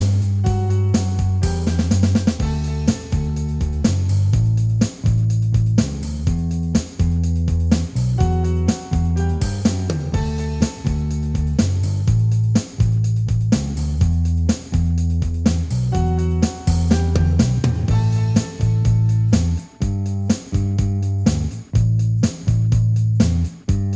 Minus Guitars Indie / Alternative 3:20 Buy £1.50